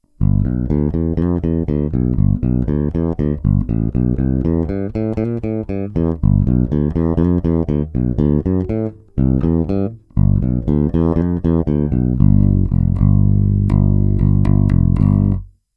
Posuďte (použity jsou struny Thomastik JR344 Jazz Rounds 43 - 89):
Oba snímače MK1